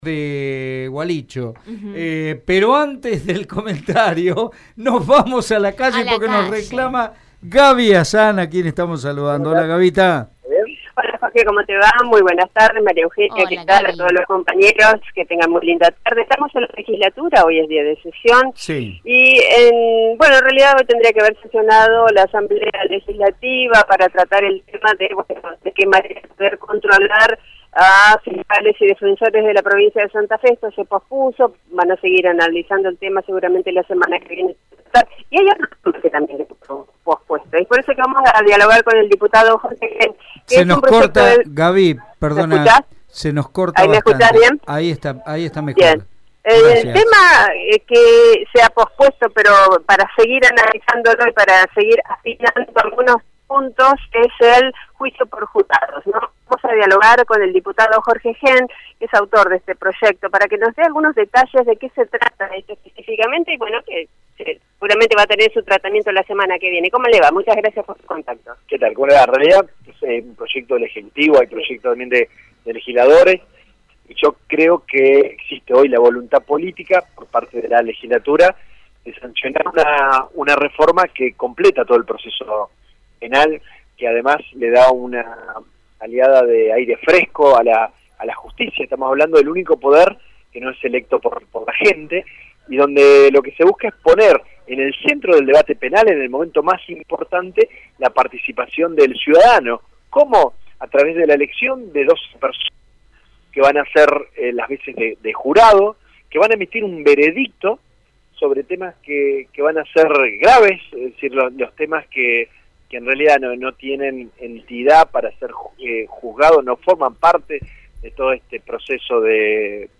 Hoy en diálogo con el Móvil de Radio EME el diputado volvió a hablar sobre el proyecto de los Juicios por Jurados, el cual se volvería a retomar la semana que viene.